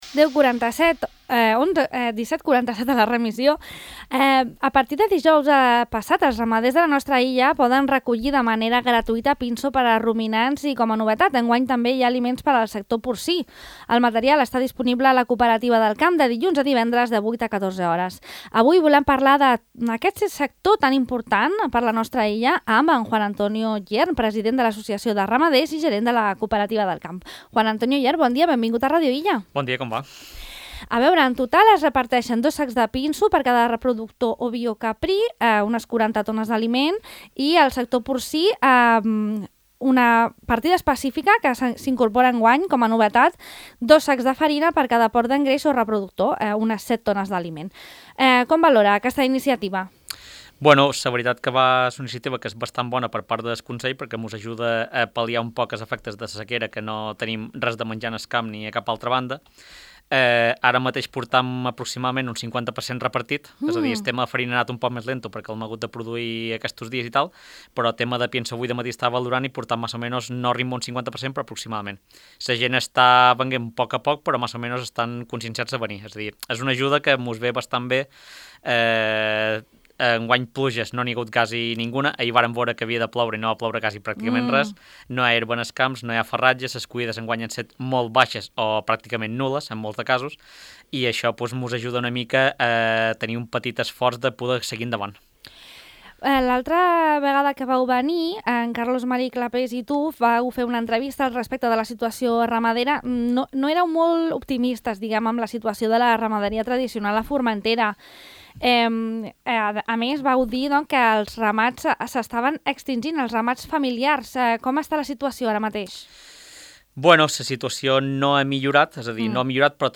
En aquest sentit, el nostre entrevistat d’avui ha explicat que entre la sequera i la manca de relleu en la ramaderia familiar, la situació no és positiva però, almenys, es manté estable respecte a l’any 2024. En l’àmbit de la producció, destaca el sector carni.